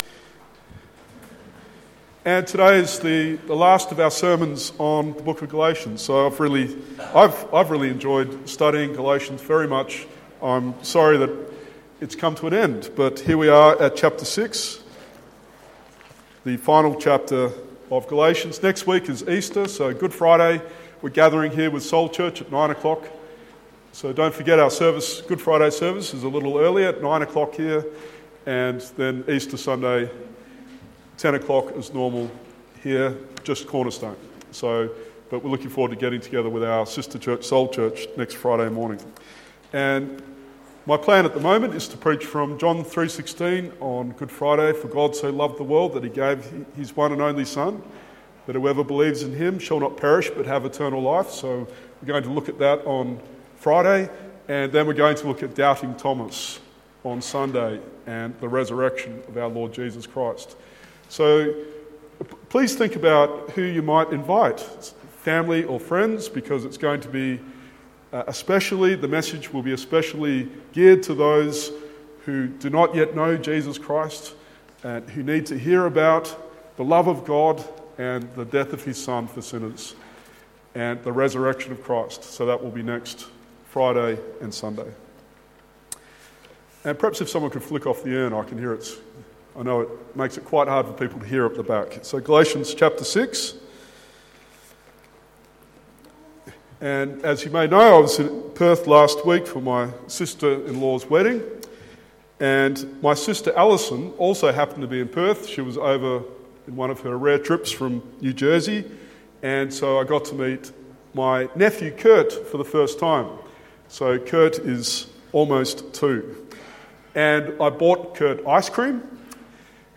Download Sermon Series: Galatians